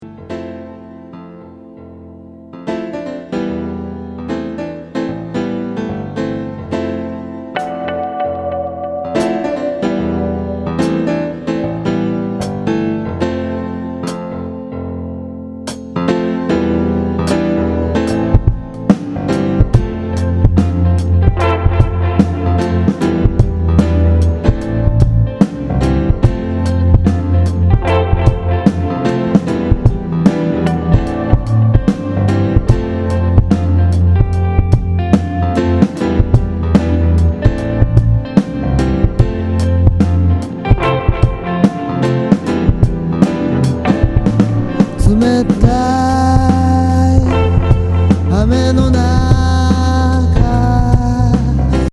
前作とはかなり様相を変え、ハウスやラウンジを強く意識した内容になっている。
全曲にポップな歌がフューチャーされている。